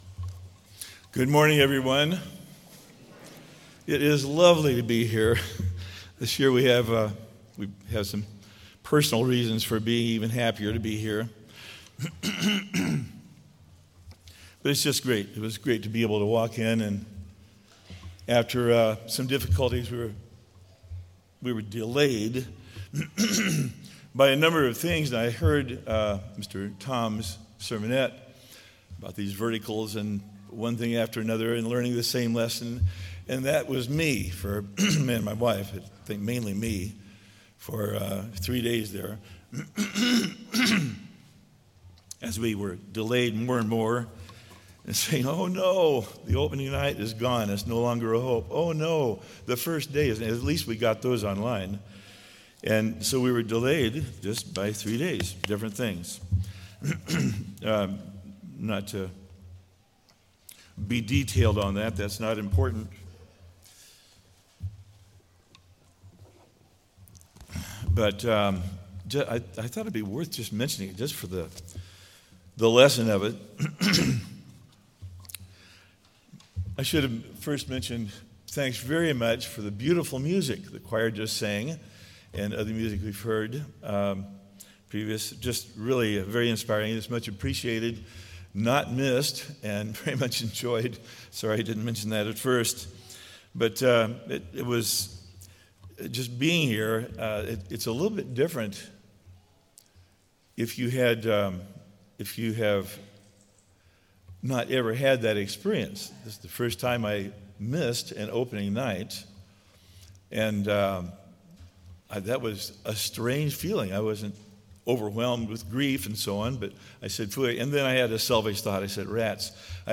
This sermon was given at the St. George, Utah 2022 Feast site.